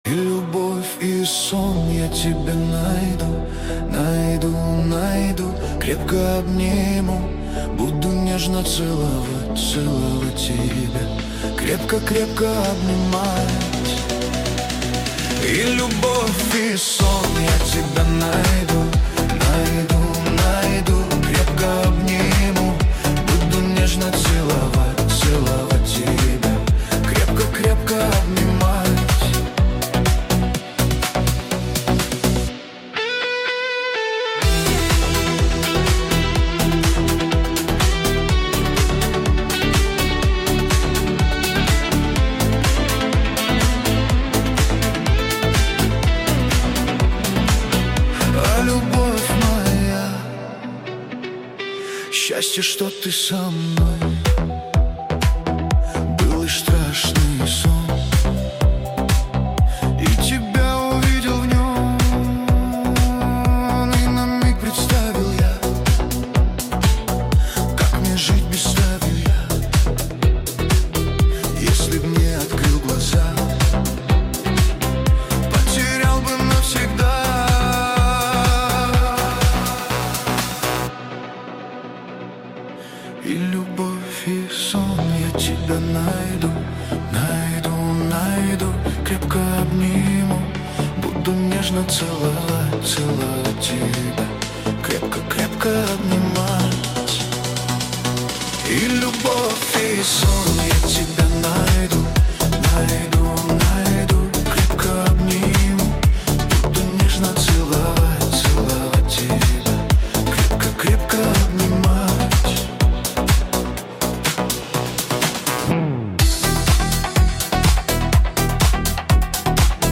Тикток ремикс